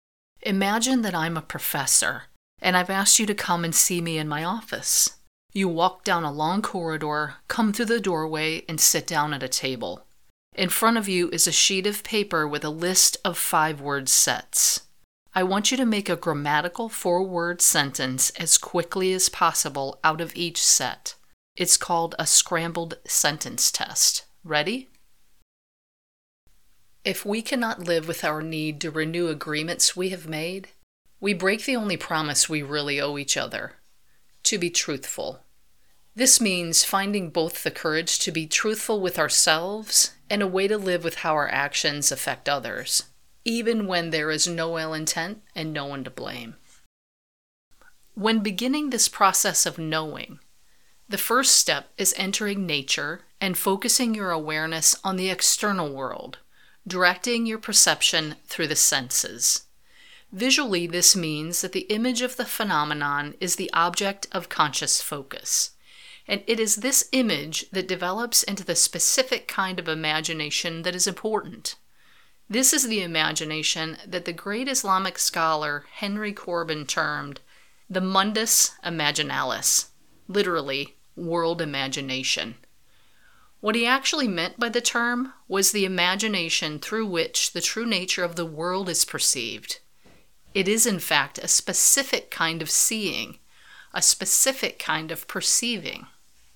Audio Book Sample Read:
non-fiction reading